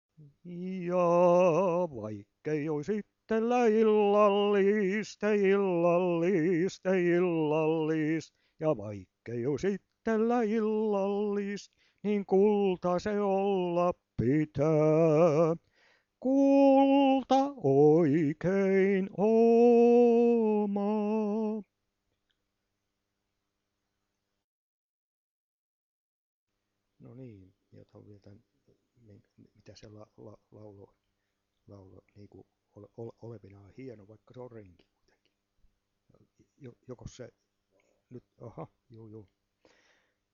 VANHOJA PIIRILEIKKILAULUJA